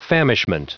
Prononciation du mot famishment en anglais (fichier audio)
Prononciation du mot : famishment